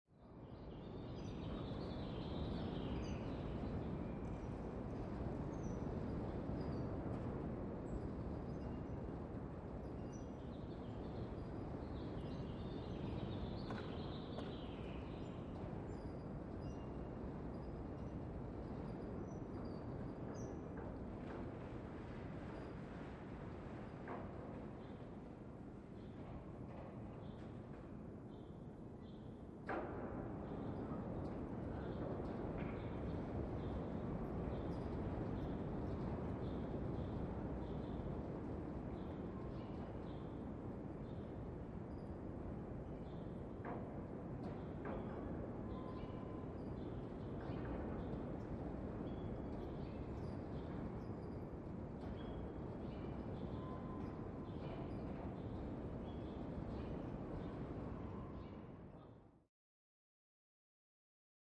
Airline Hanger | Sneak On The Lot
Large Airy Plane Hanger With Birds And Distant Hammering, Very Reverberant.